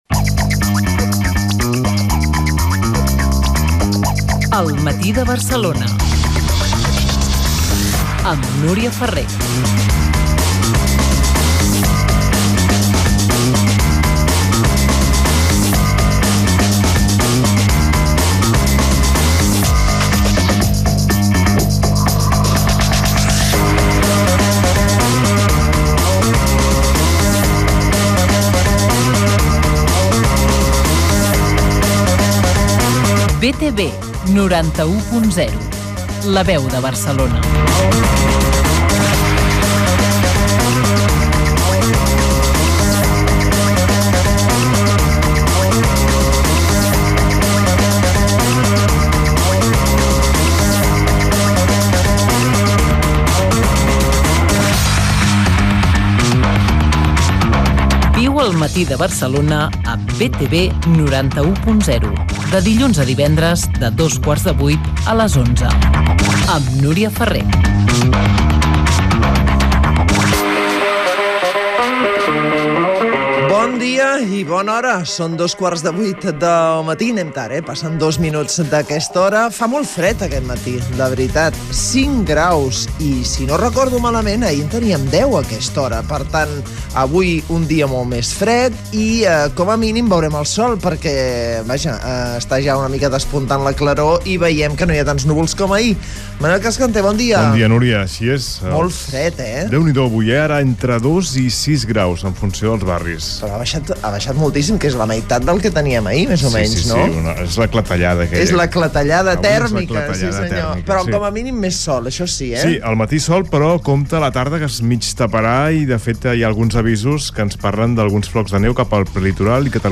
Indicatius del programa i de l'emissora, hora, estat del temps, el trànsit, esports, resum informatiu: informe d'Amnistia Internacional sobre les càrregues policials del dia 1 d'octubre de 2017, hora, indicatiu
Informatiu